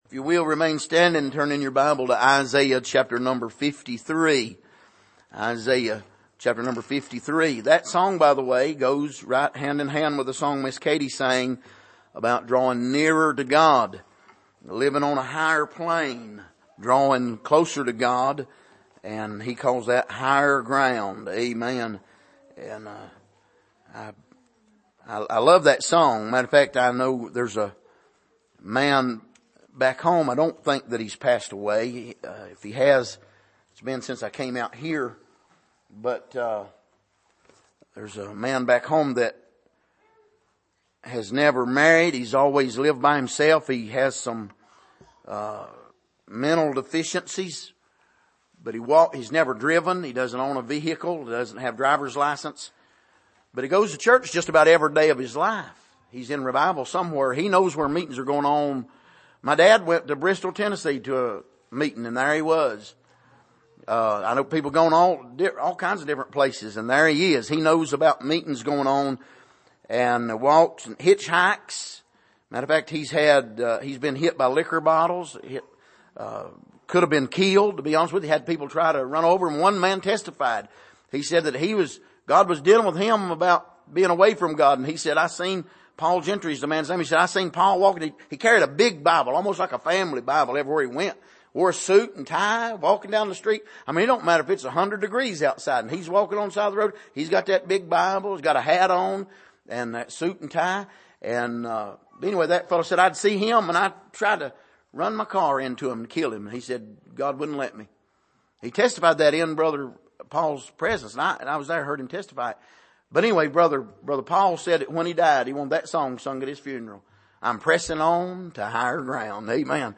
Isaiah 53:1-12 Service: Sunday Morning Why Did Jesus Suffer and Die?